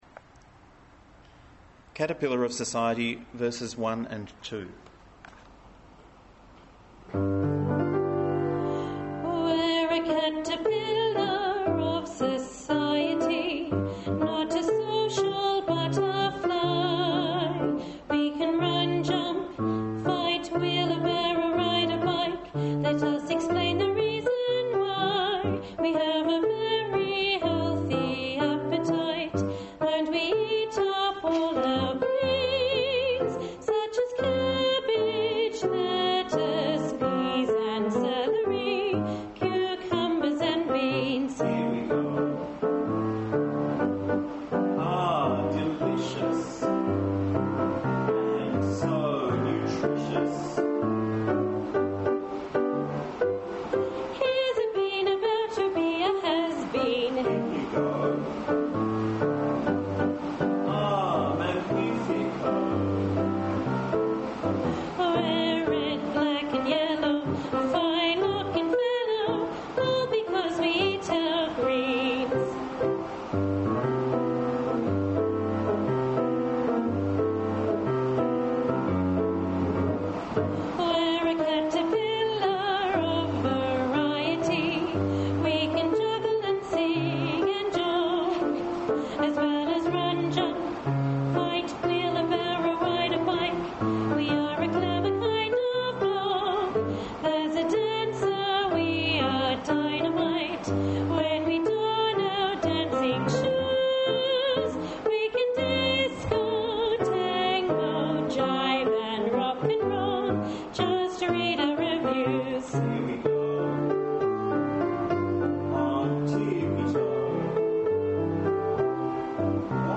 18 WESLEY SMITH Caterpillar UNISON Verses 1 & 2